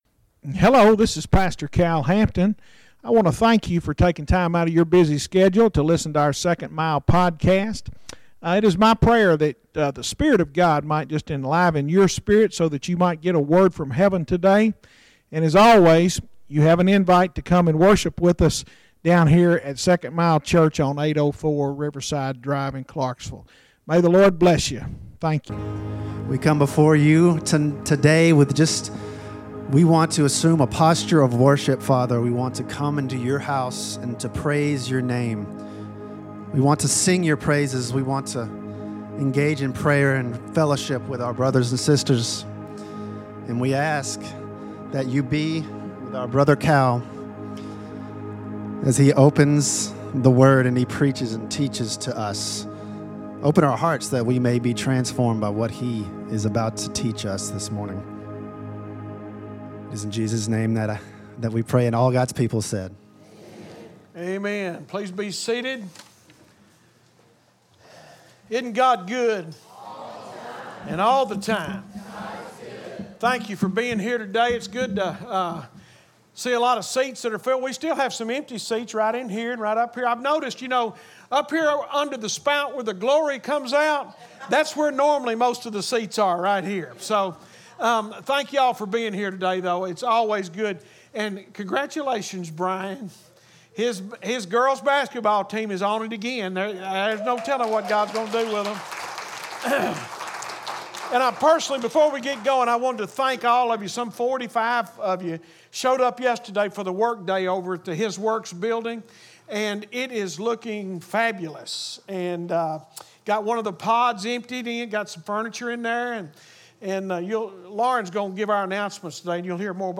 Bible study, Easter